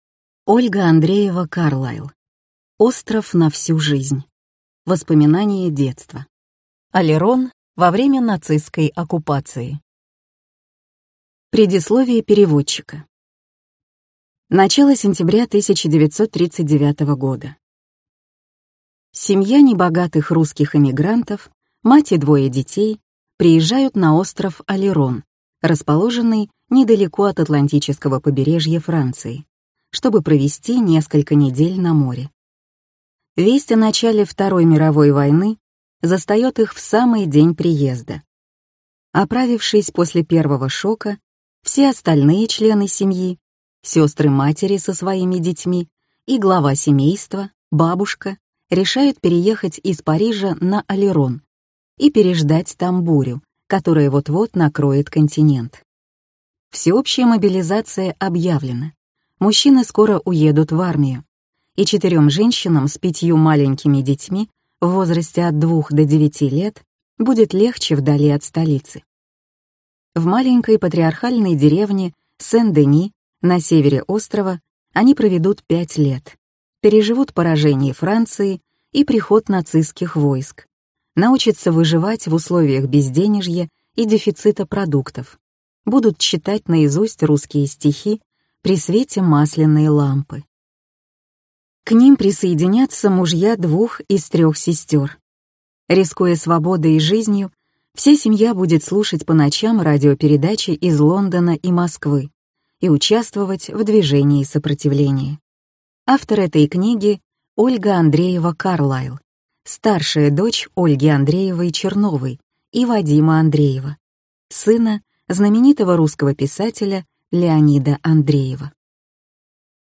Аудиокнига Остров на всю жизнь. Воспоминания детства. Олерон во время нацистской оккупации | Библиотека аудиокниг